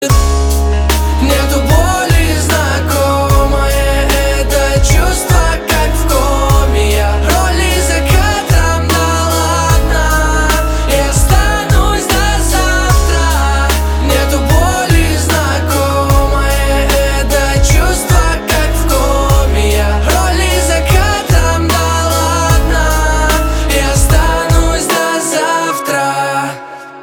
• Качество: 256, Stereo
лирика
русский рэп